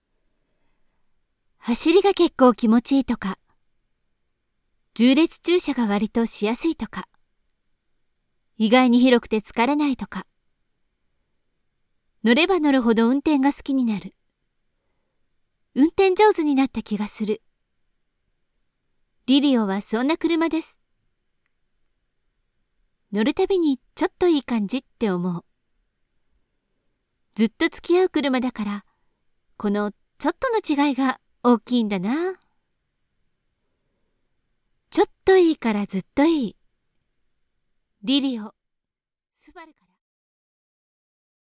Commercial, Versatile, Reliable, Corporate, Young
Commercial